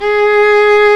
Index of /90_sSampleCDs/Roland L-CD702/VOL-1/STR_Viola Solo/STR_Vla1 Bryt vb